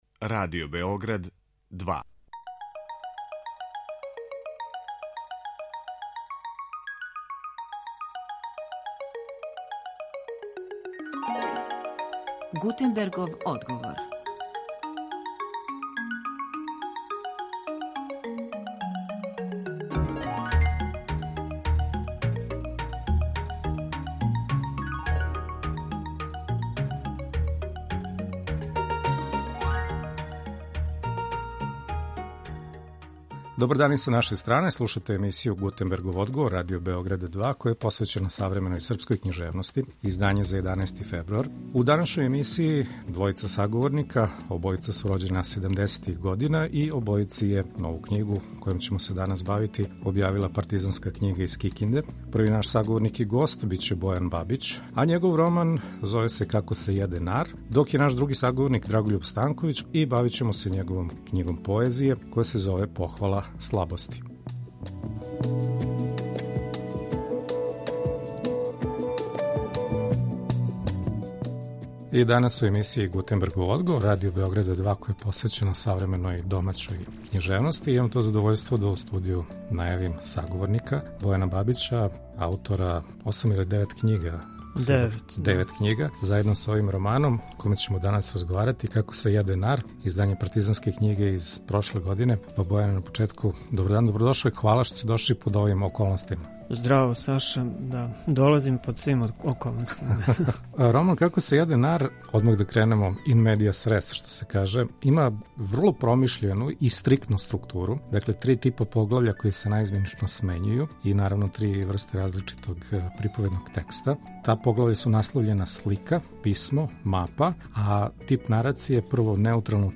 У данашњој емисији наши саговорници су аутори рођени 1970-их година, који пишу прозу и поезију, а чије су последње књиге објављене у Партизанској књизи из Кикинде прошле године.